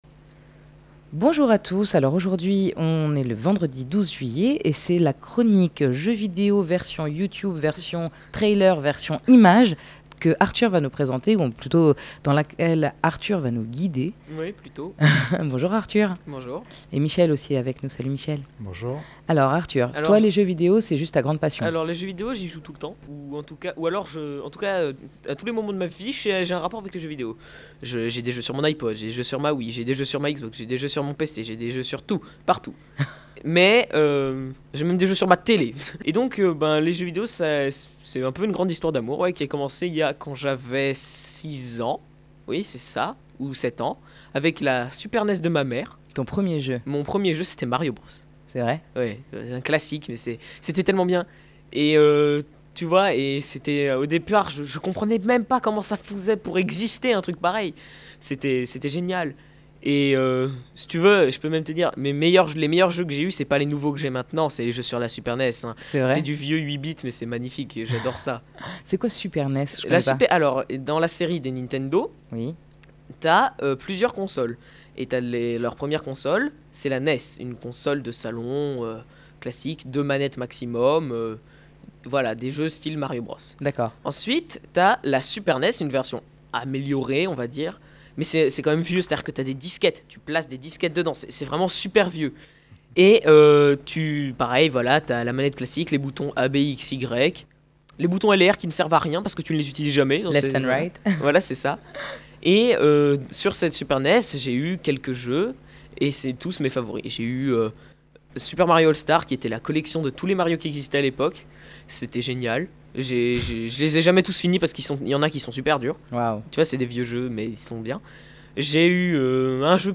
Avé les cigales en fond sonore!